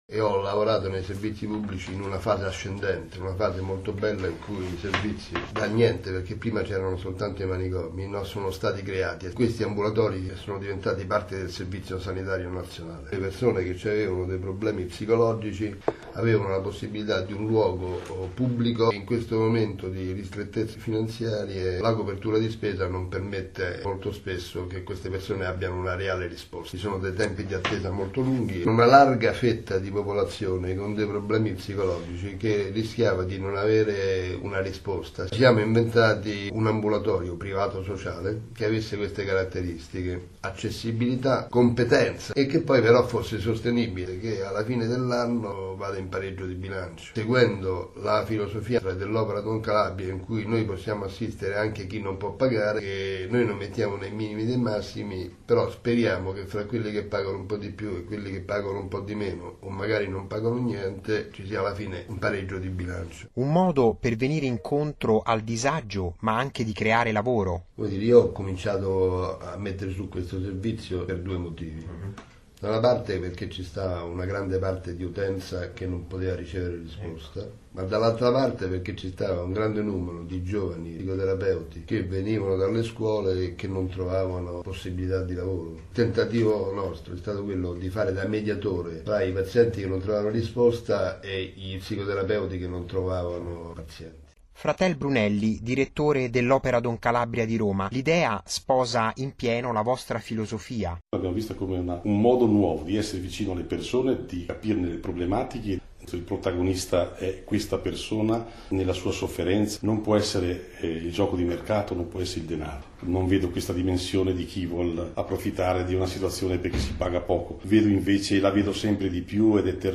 da Radio Vaticana, 7/3/2012